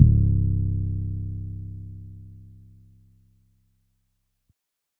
808 (Dirty).wav